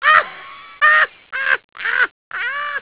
and now the tasty sound effects...
crazy laugh
laugh.au